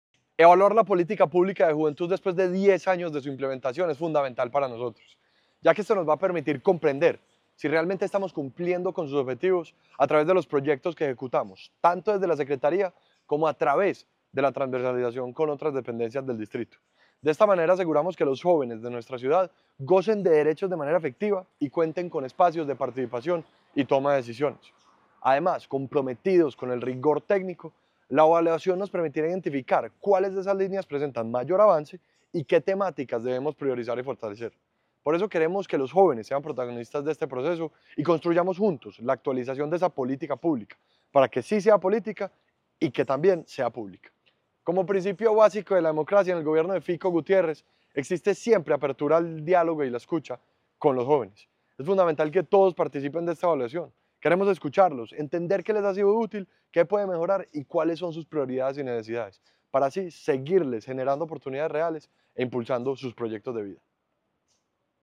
Palabras de Ricardo Jaramillo, secretario de la Juventud